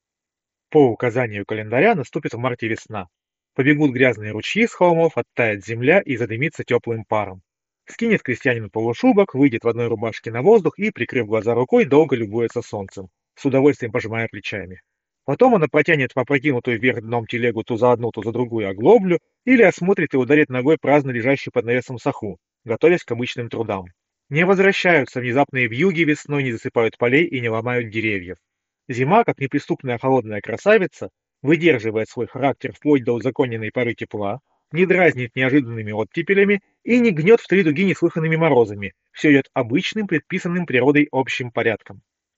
На первом речь записана с помощью микрофонов гарнитуры.
Запись с микрофона гарнитуры
Голос передается достаточно полновесно, хотя и с легким оттенком гулкости. В среднем диапазоне ощущается небольшой недостаток, но на разборчивости это совершенно не сказывается — речь остается четкой и легко читаемой.